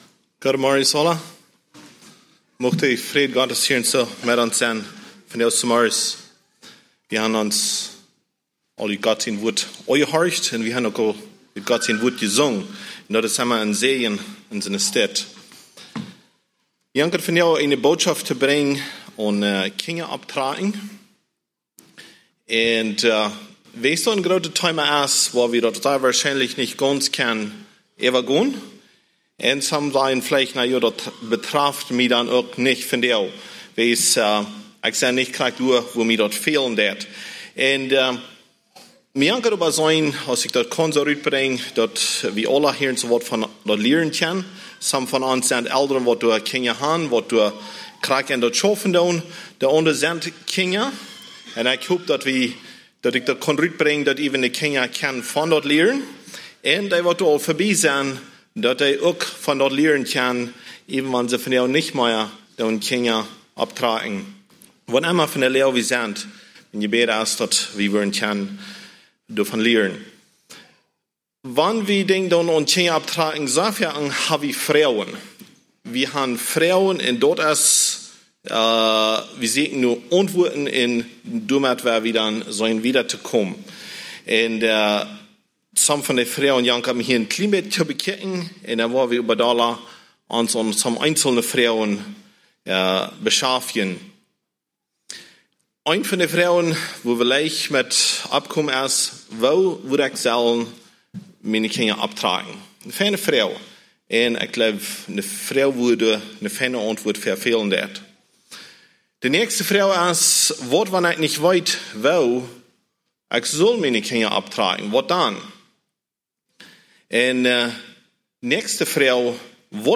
message brought on Aug. 21, 2022